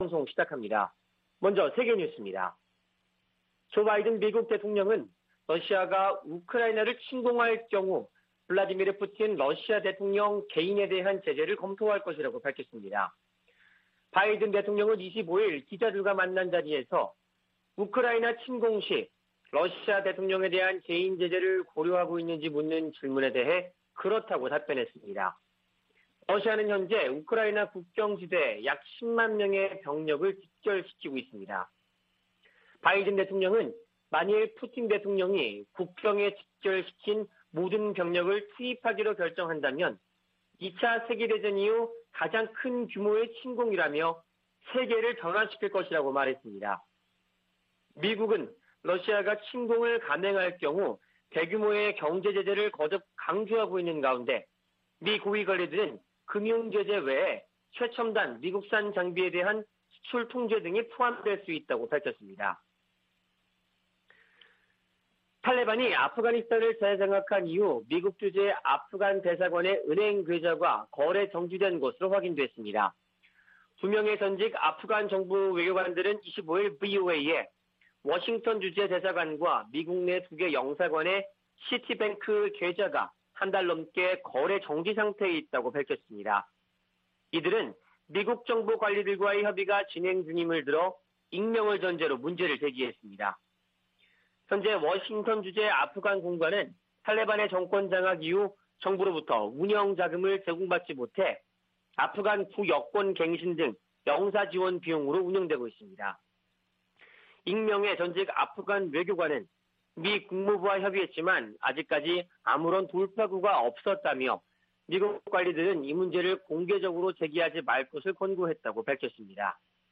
VOA 한국어 '출발 뉴스 쇼', 2021년 1월 27일 방송입니다. 미 국무부는 북한이 순항미사일 2발을 발사한 것과 관련, 여전히 평가 중이라고 밝히고, 대북 정책 목표는 여전히 한반도의 완전한 비핵화라고 확인했습니다.